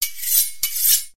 knife.ogg